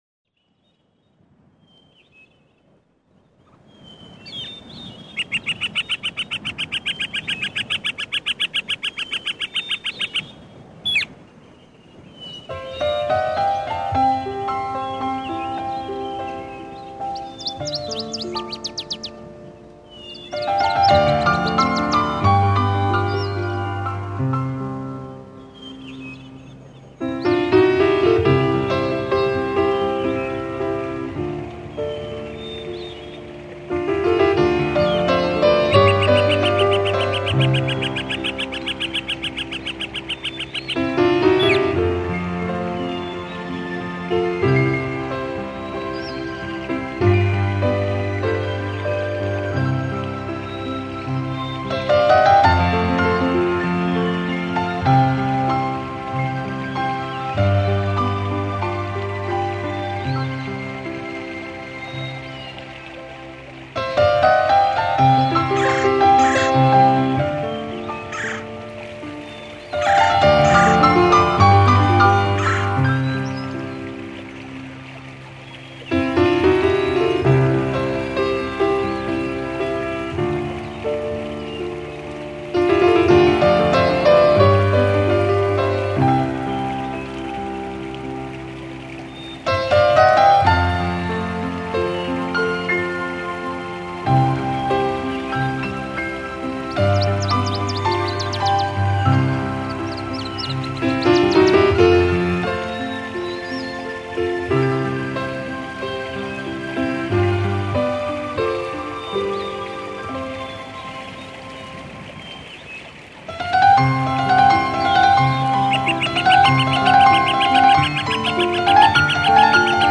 营造出泛着银晖的浪漫情境。